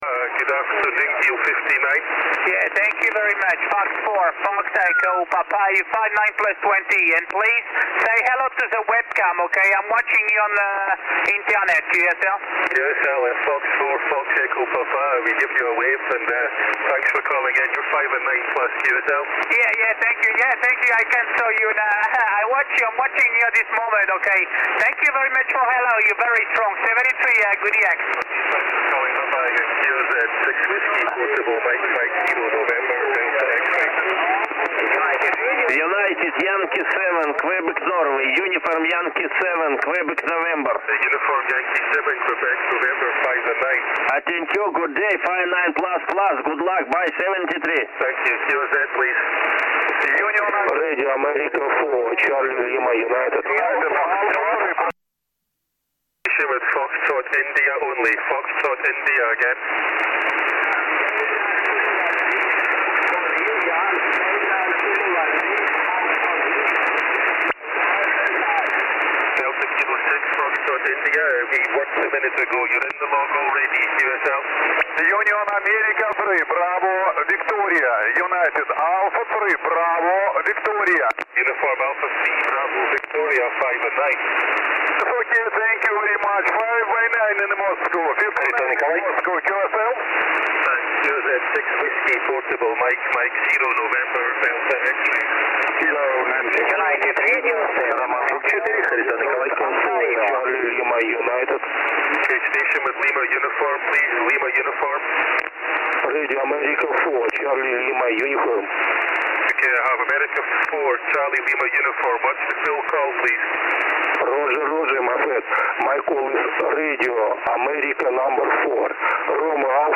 15mt SSB